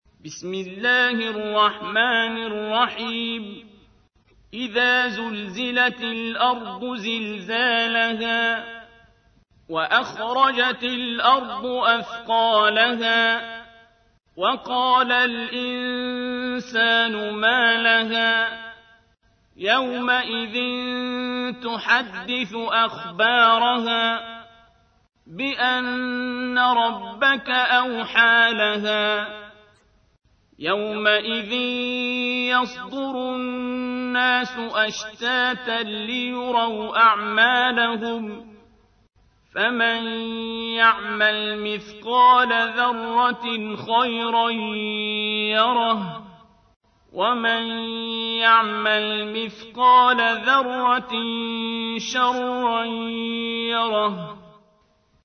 تحميل : 99. سورة الزلزلة / القارئ عبد الباسط عبد الصمد / القرآن الكريم / موقع يا حسين